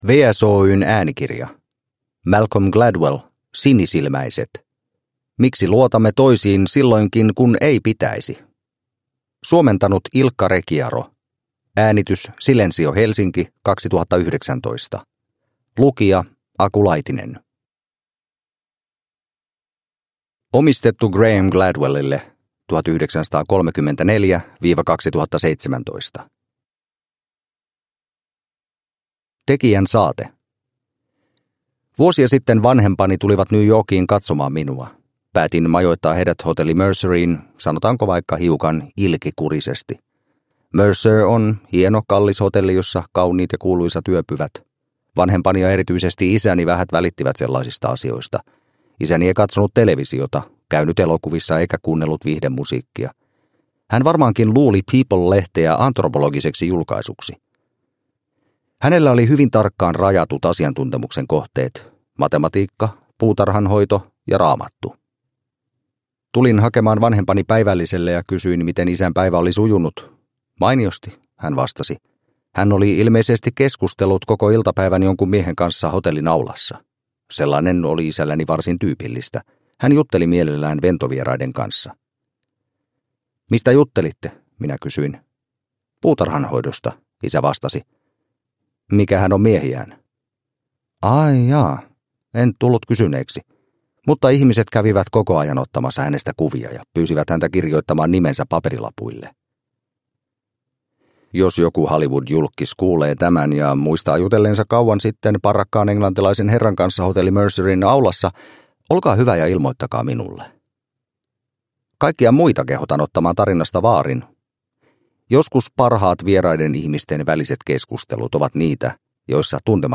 Sinisilmäiset – Ljudbok – Laddas ner